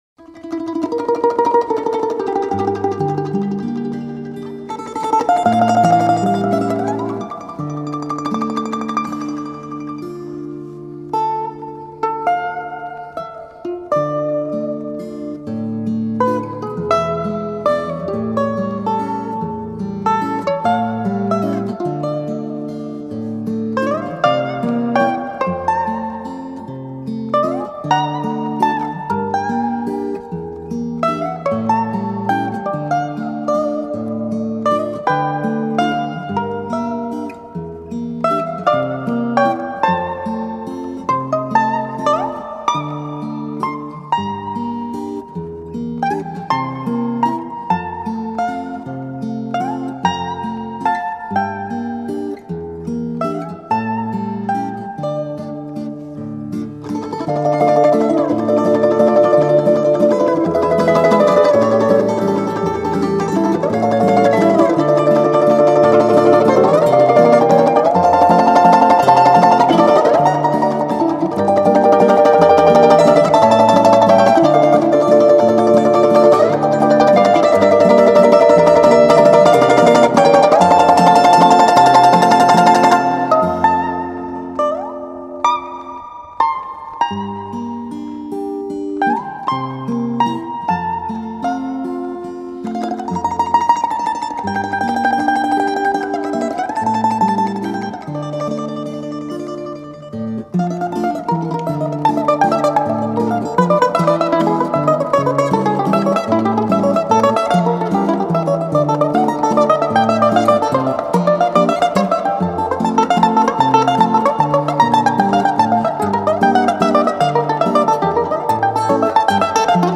балалаешный вариант